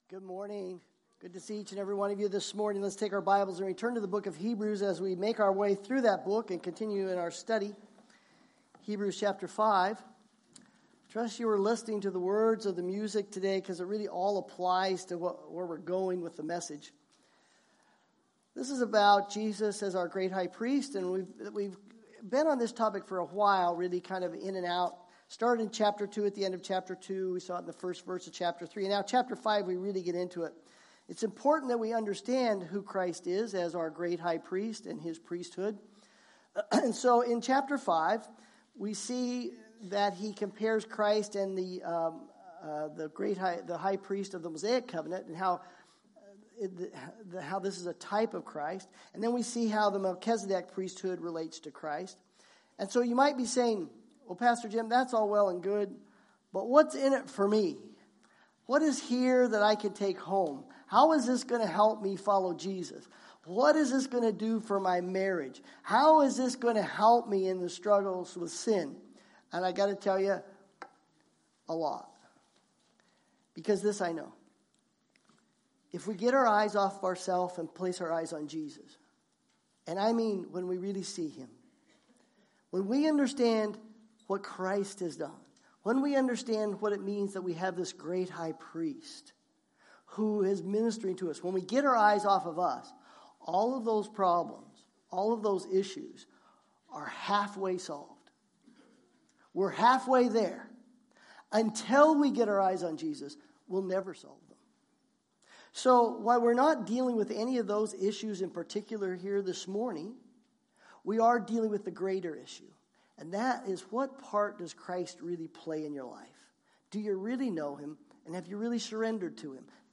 Bible Text: Hebrews 5 | Preacher